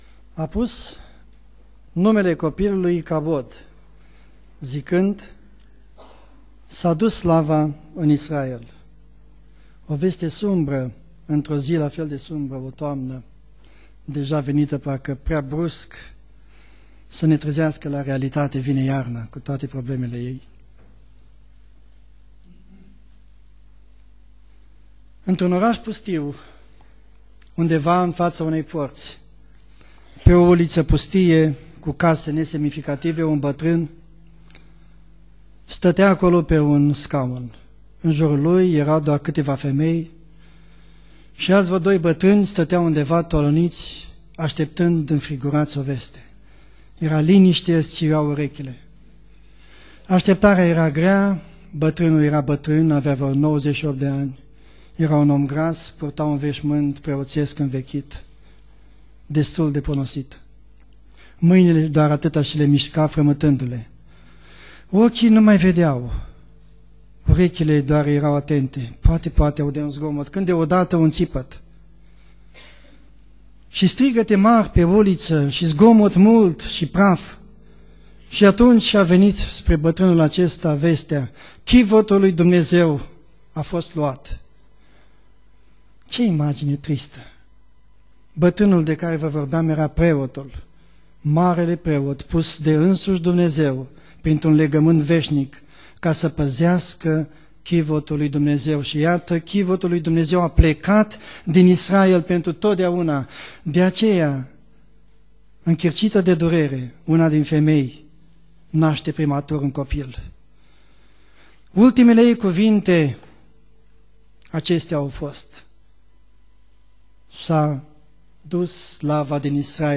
Predica 1 Samuel cap 2:12-4:22 Exegeza